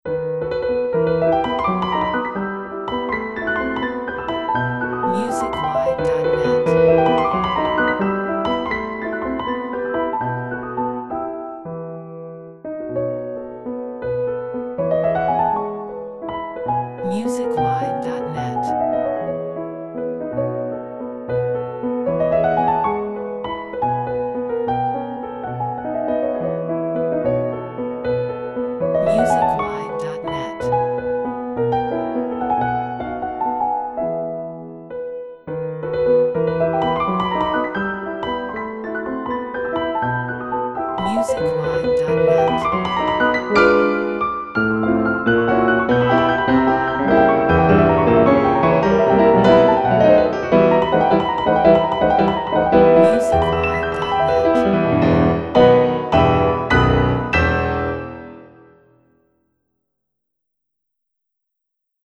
The complete piano Waltz in E minor, WN 29 by Frédéric Chopin.